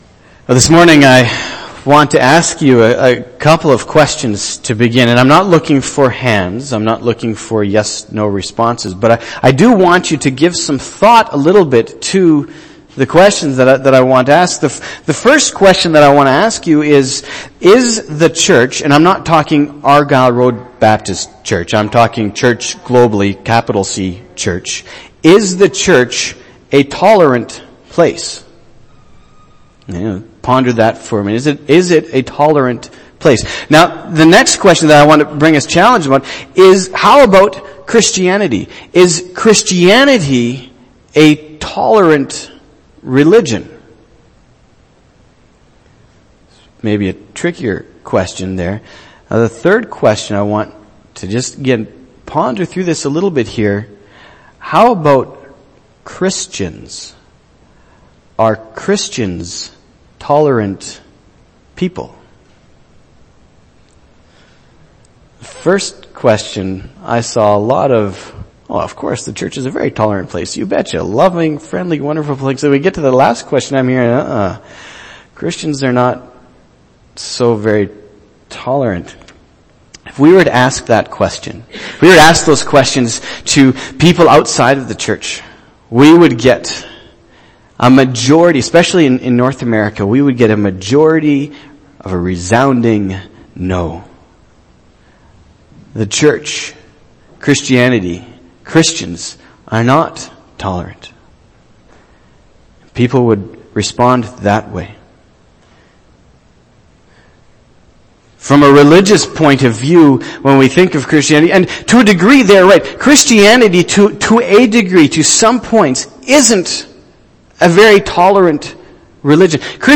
september-6-2015-sermon-tolerance.mp3